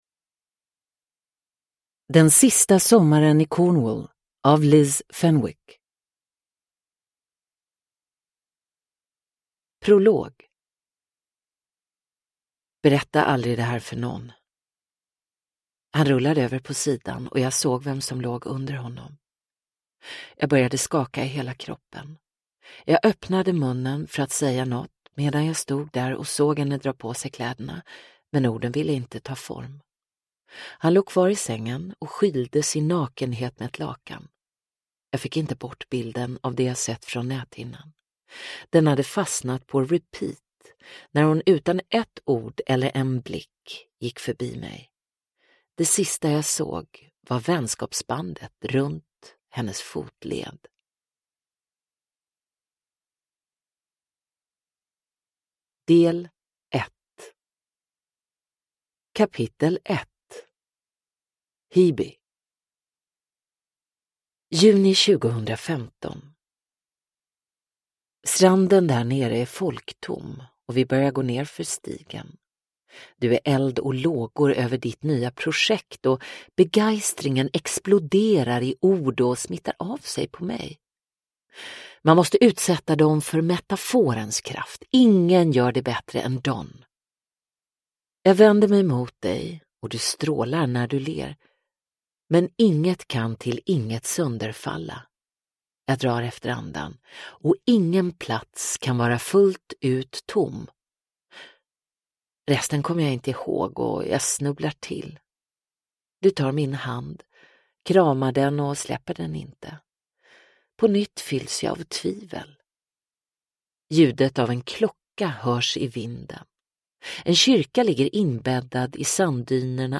Den sista sommaren i Cornwall – Ljudbok